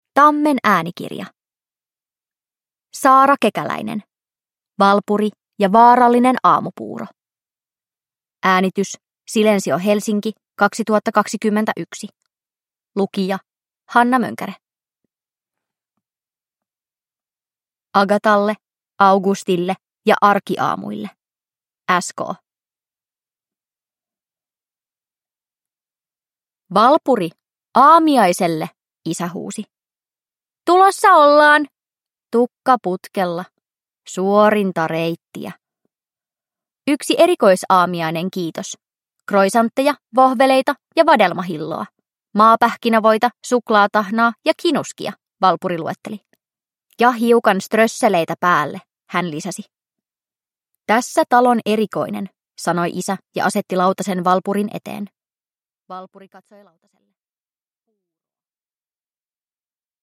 Valpuri ja vaarallinen aamupuuro – Ljudbok